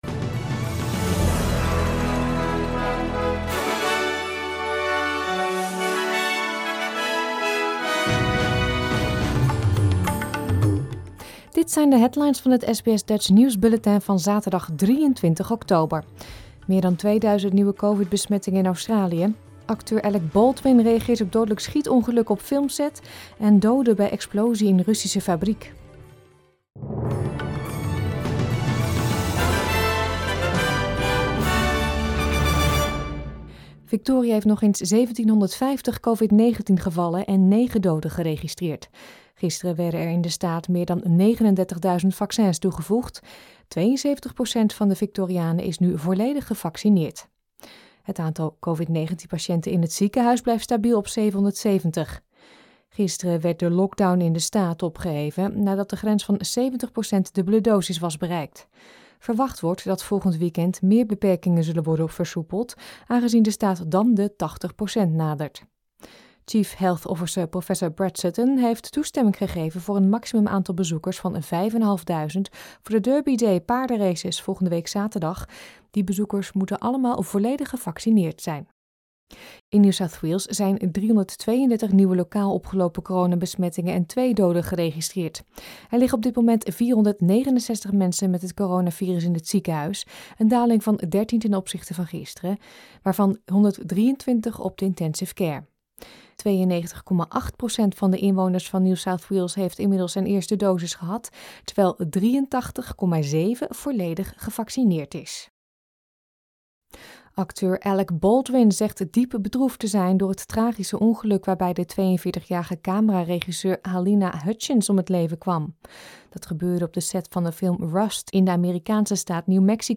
Het Nederlands / Australisch SBS Dutch nieuwsbulletin van zaterdag 23 oktober 2021